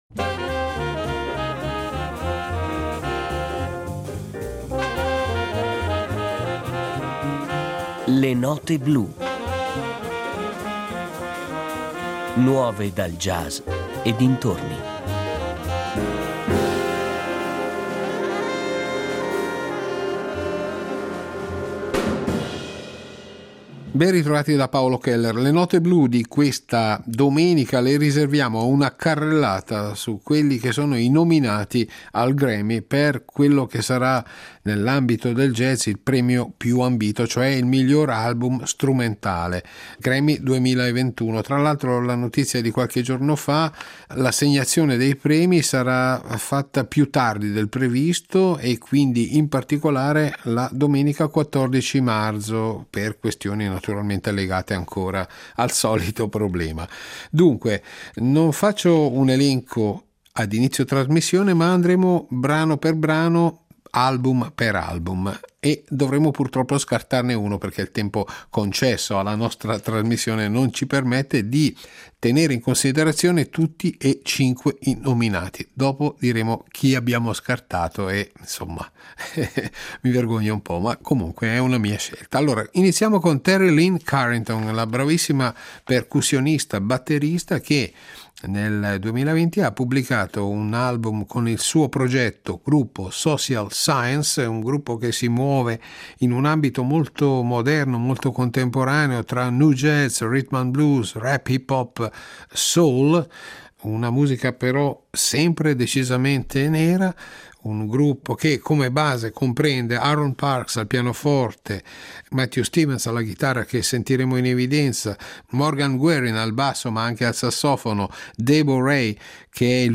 Miglior album strumentale jazz